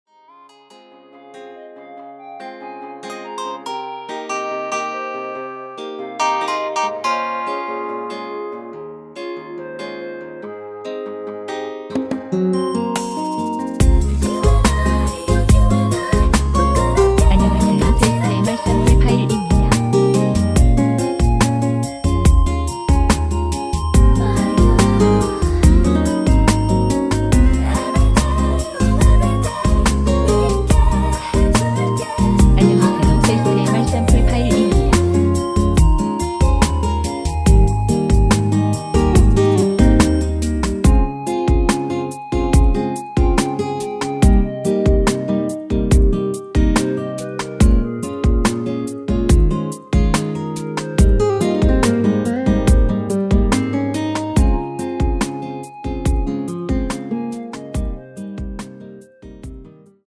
미리듣기에서 나오는 부분이 이곡의 코러스 전부 입니다.(원곡에 코러스가 다른 부분은 없습니다.)
원키에서(-1)내린 멜로디와 코러스 포함된 MR입니다.(미리듣기 확인)
Ab
앞부분30초, 뒷부분30초씩 편집해서 올려 드리고 있습니다.
중간에 음이 끈어지고 다시 나오는 이유는